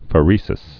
(fə-rēsĭs, fĕrə-)